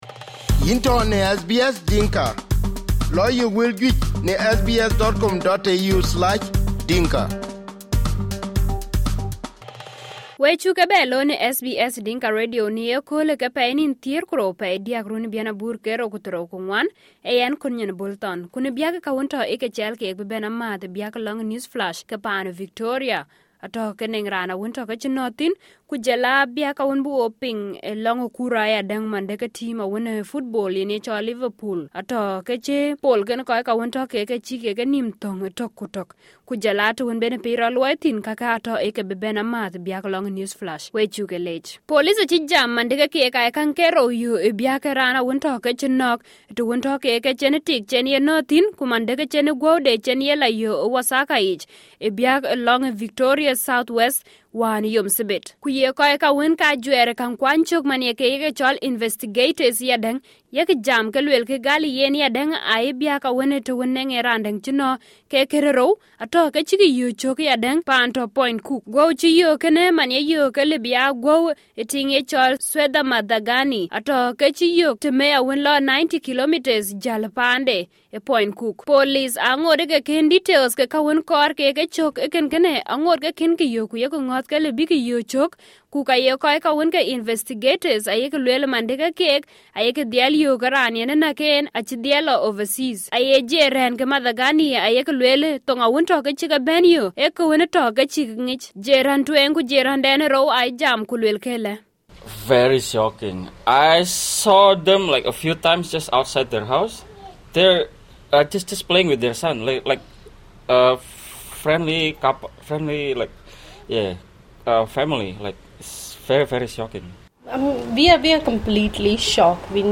SBS Dinka News Flash 12/03/2024